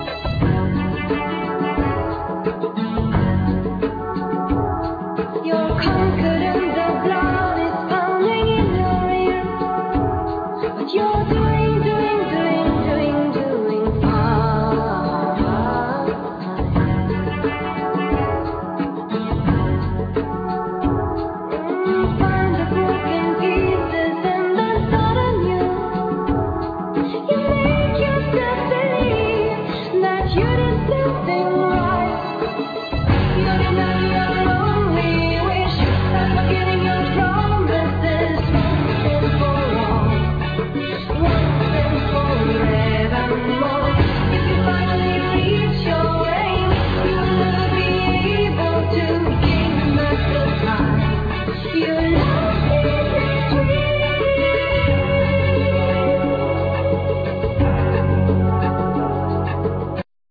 Vocal, Keyboards, Piano
Keyboards, Programming, Piano, TR-808
Bouzouki, Mandlin, Programming, Bass, Guitar, Vocal
Flugelhorn, Trumpet
Guitar, Drums
Violin, Viola, String arrangement
Cello